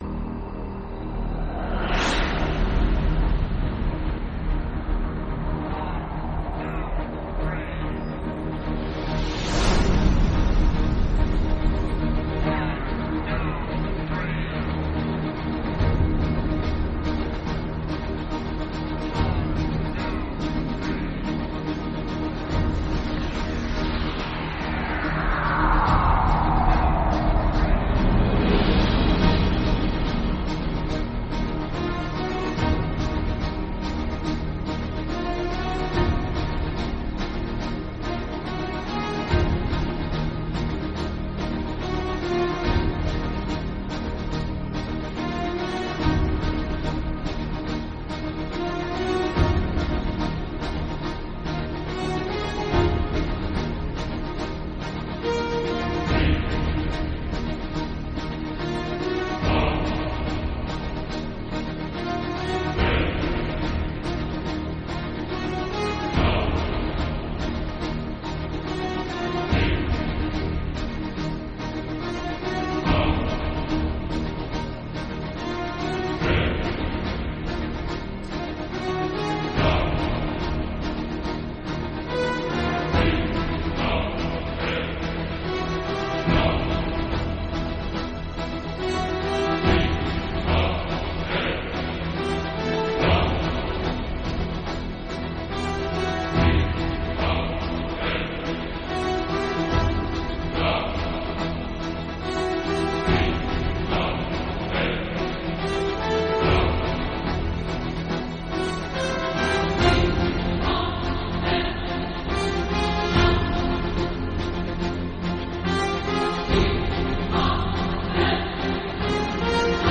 Electronic, Choral Symphony